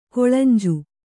♪ koḷanju